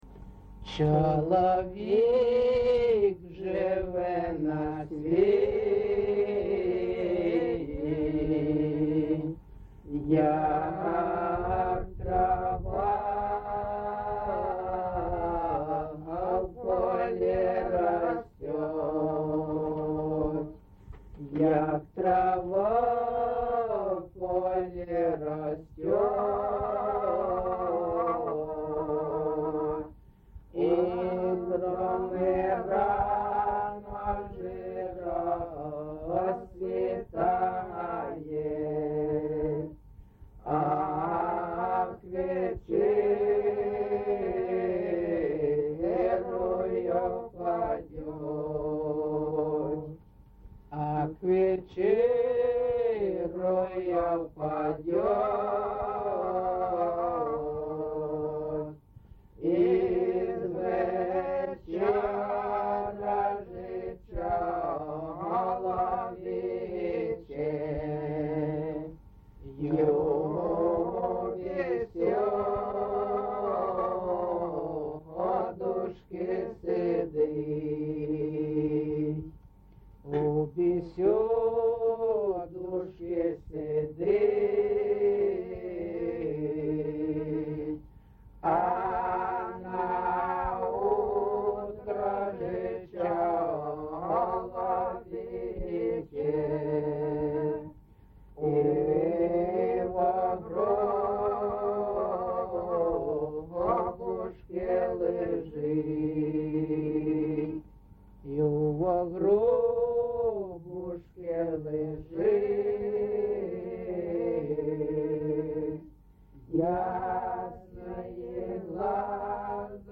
GenrePsalm
Recording locationMykhailivka, Shakhtarskyi (Horlivskyi) district, Donetsk obl., Ukraine, Sloboda Ukraine